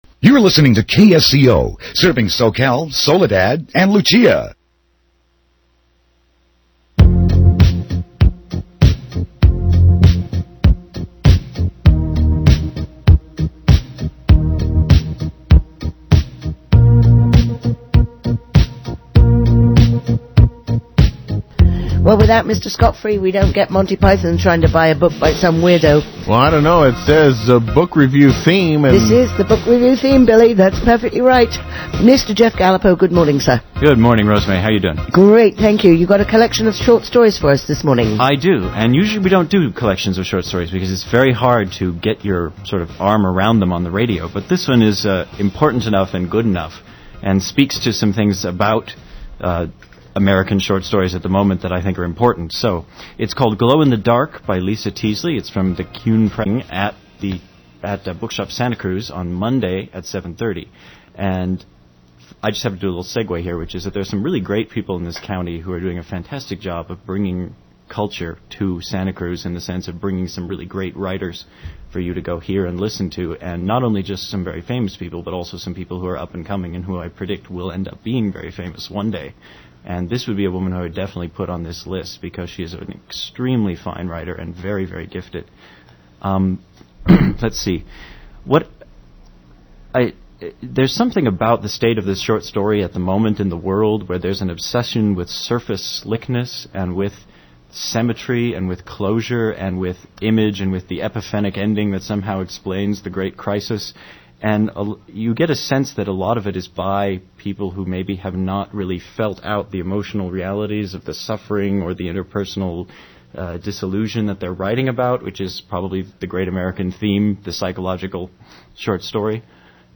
KSCO AM review